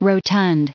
added pronounciation and merriam webster audio
1986_rotund.ogg